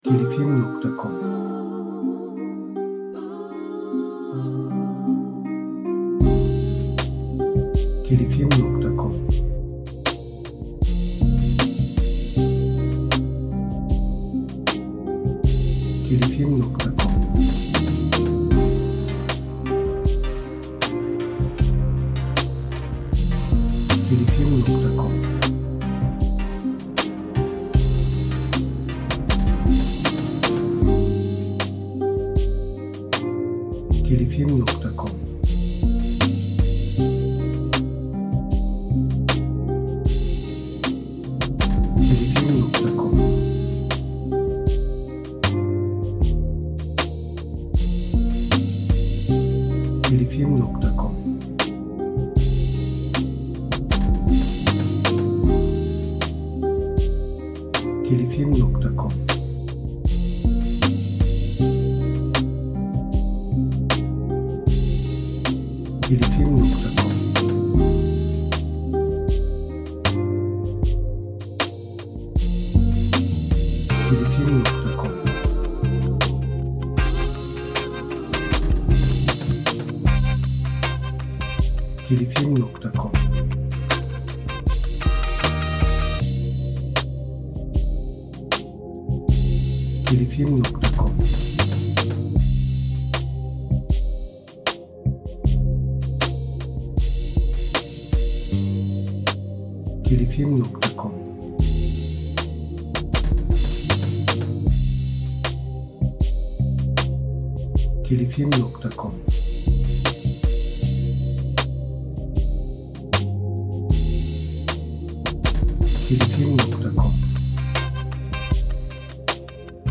Eser Türü : Müzikal Tema Eser Tipi : Enstrümental